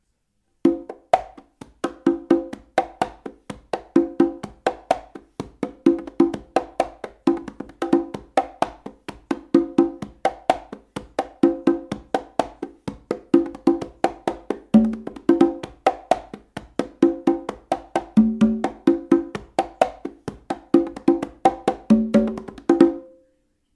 Tumbao Fill 1
This is actually 2 different fills that go along well.
tumbaofill1.mp3